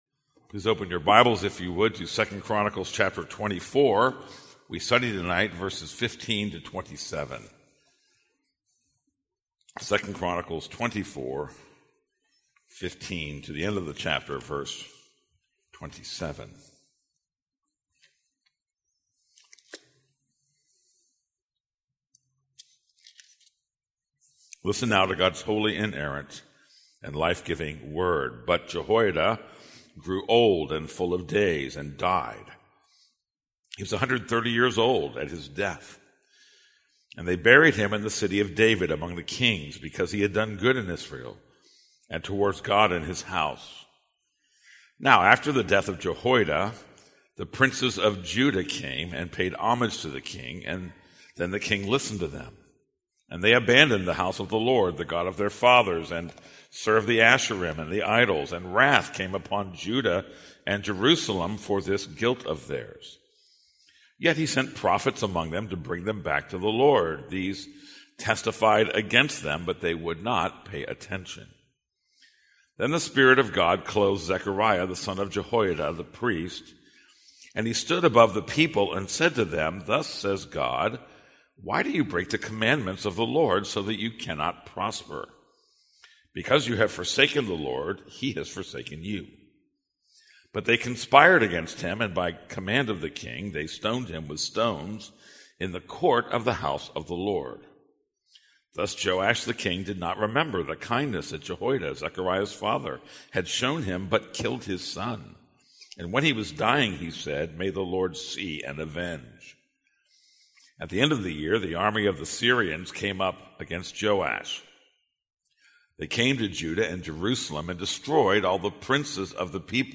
This is a sermon on 2 Chronicles 24:15-27.